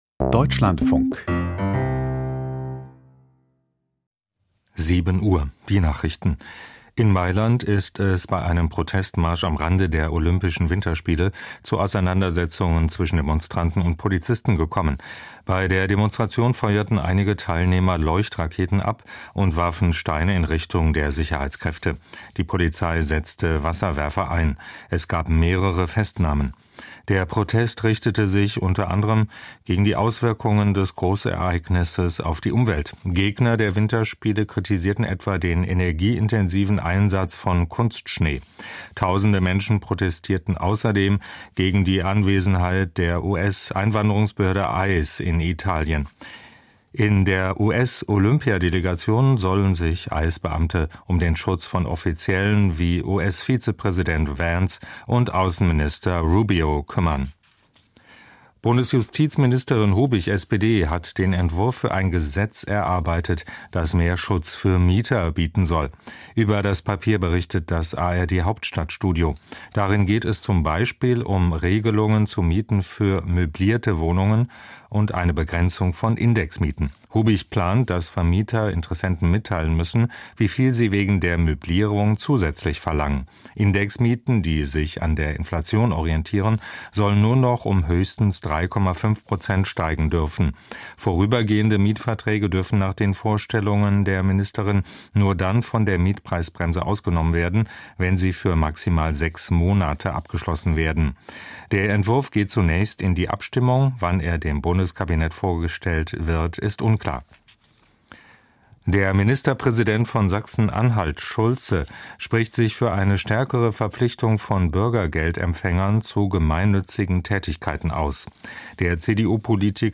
Die Nachrichten vom 08.02.2026, 07:00 Uhr